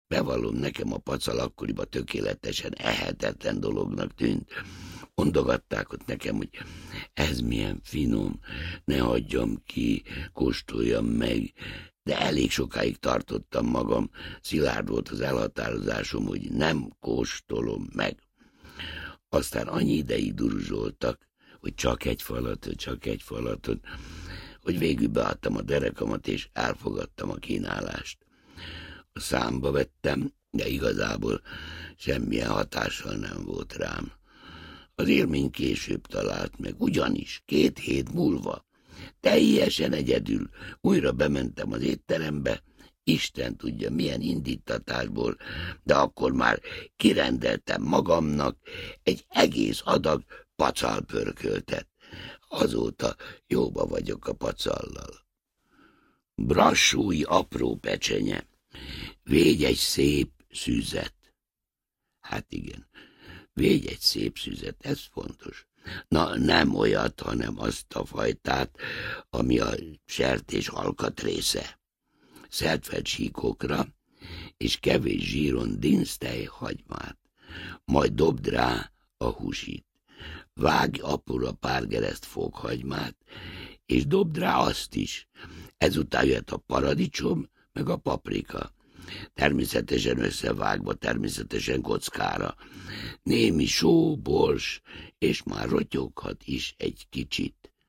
80 év, 80 recept, 80 történet (Online hangoskönyv) A szerző, Bodrogi Gyula előadásában Bodrogi Gyula Hallgass bele!
Online hangoskönyv / Gasztronómia / Szakácskönyvek Szállítás: Azonnal A hangoskönyv a szerző, Bodrogi Gyula előadásában hallható.